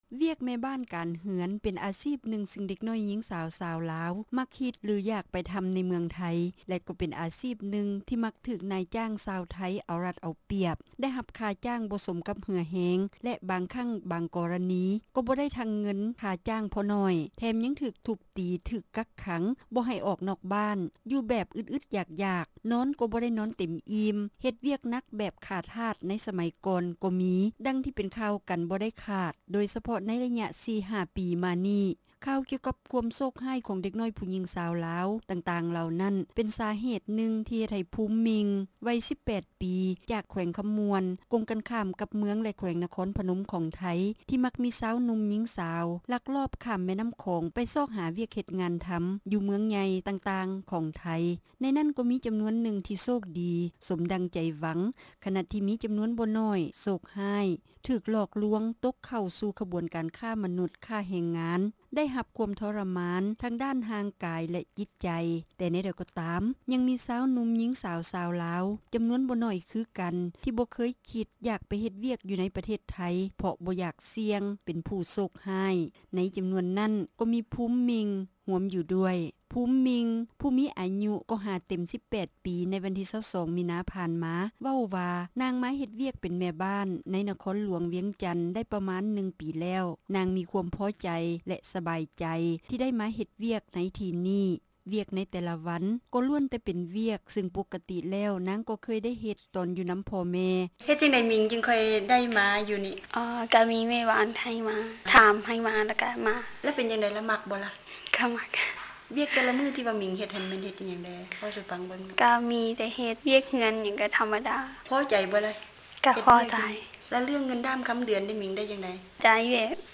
ຈະນໍາເອົາການໂອ້ລົມຫລີ້ນ ກັບຜູ້ຍີງສາວຊາວລາວ